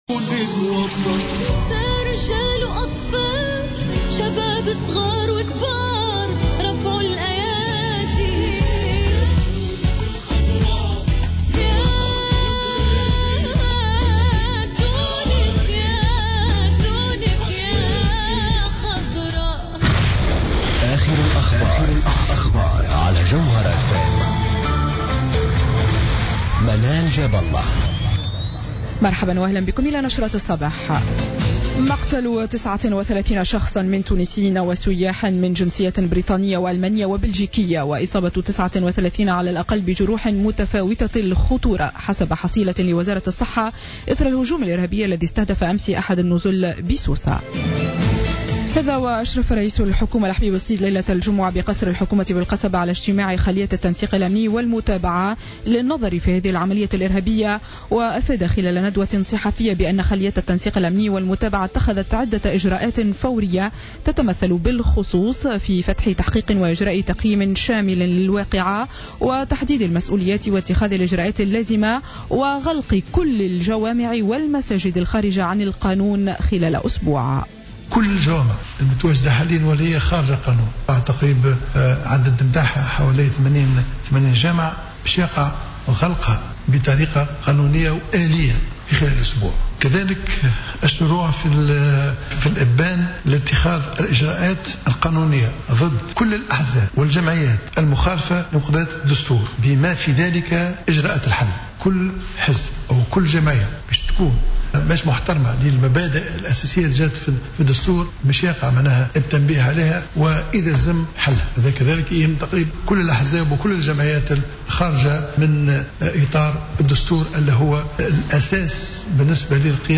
نشرة أخبار السابعة صباحا ليوم السبت 27 جوان 2015